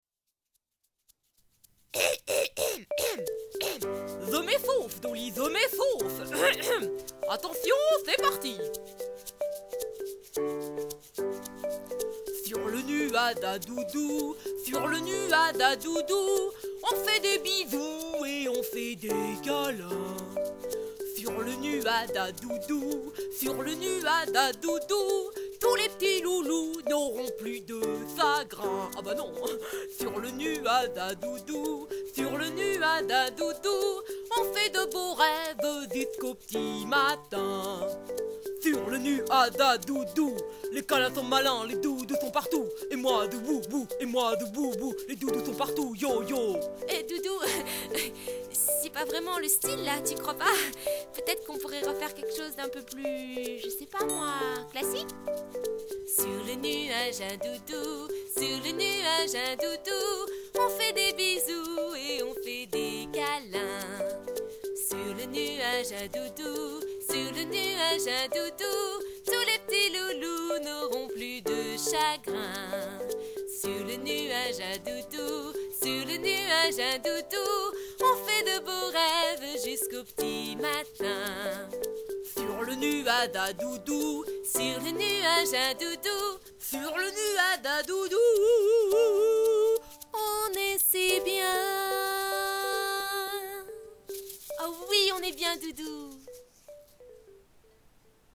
Gaieté et poésie sont au programme de ce spectacle musical pour les tout-petits!